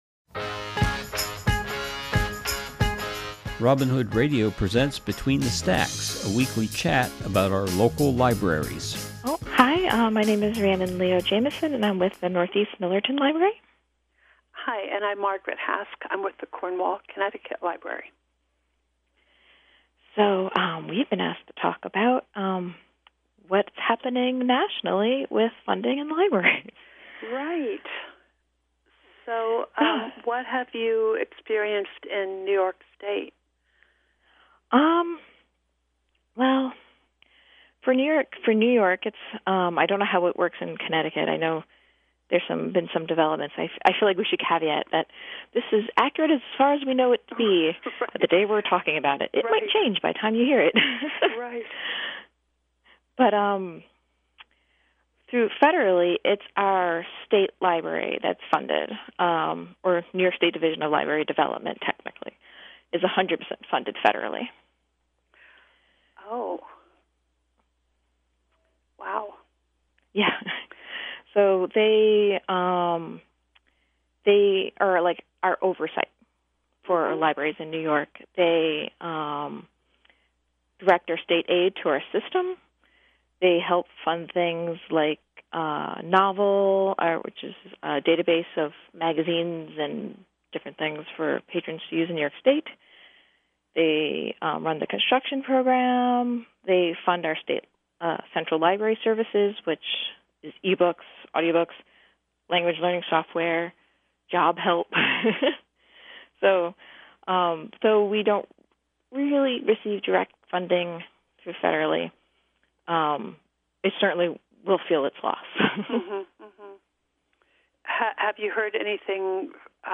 This program is a conversation